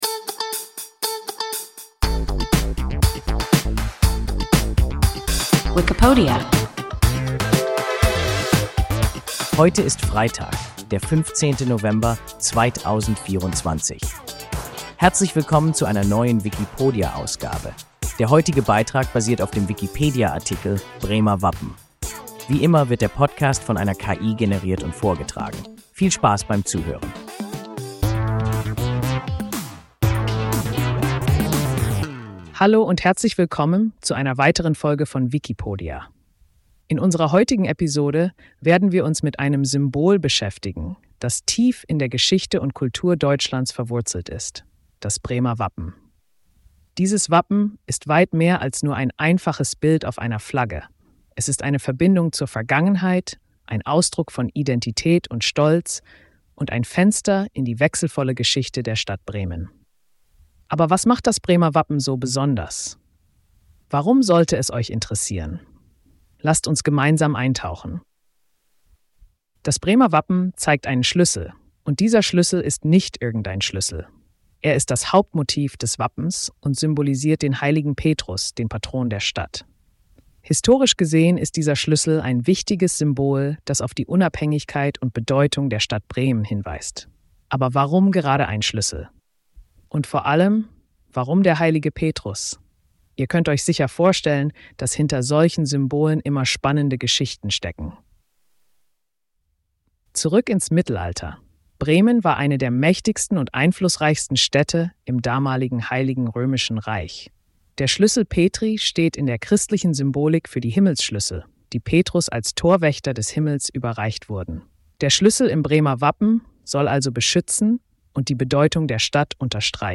Bremer Wappen – WIKIPODIA – ein KI Podcast